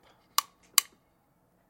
浴室 " 打开浴帘
描述：打开浴帘
声道立体声